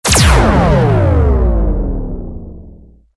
laserblast.mp3